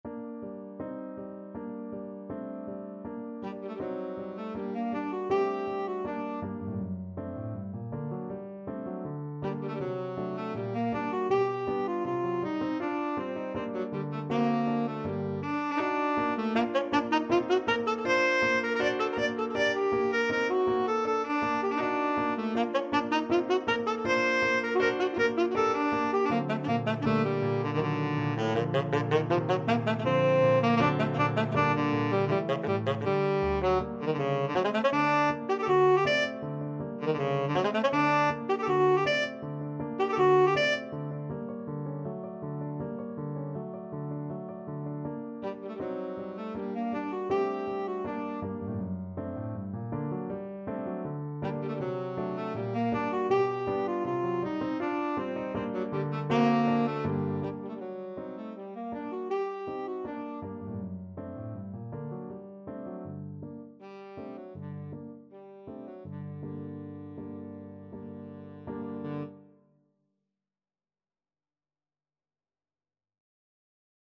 = 80 Andante espressivo
2/4 (View more 2/4 Music)
Classical (View more Classical Tenor Saxophone Music)